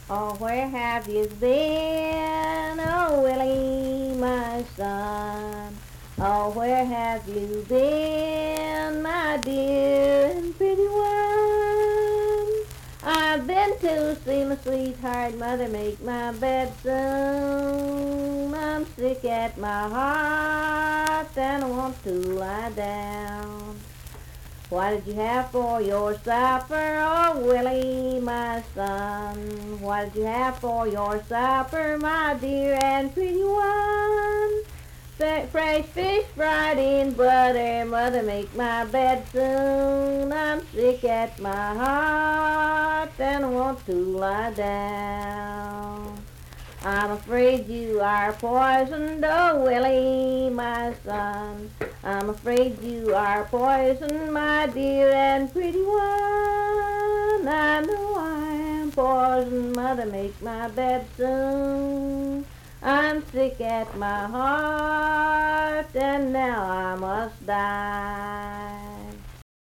Unaccompanied vocal music
Voice (sung)
Nicholas County (W. Va.), Richwood (W. Va.)